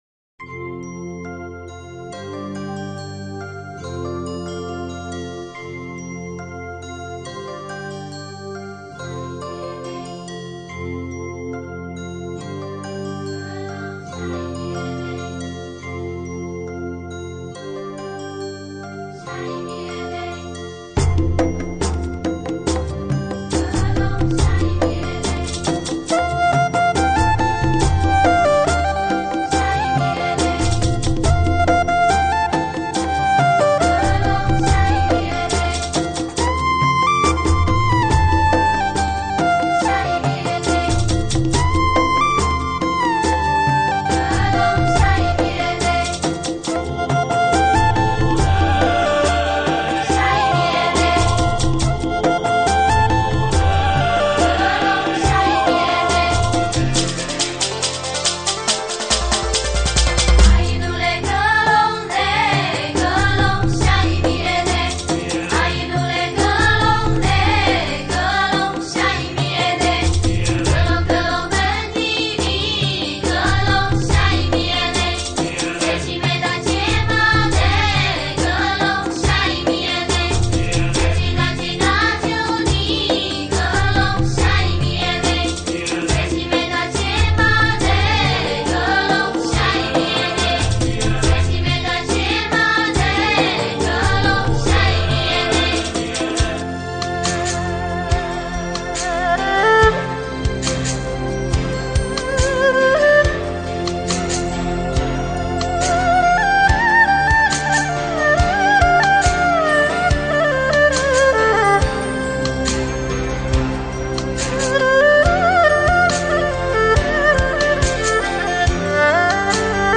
例如毛難族人以二聲部重唱與蘆笙、竹笛的清音， 說出了他們以「花竹帽」定情的愛情故事。